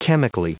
Prononciation du mot chemically en anglais (fichier audio)
Prononciation du mot : chemically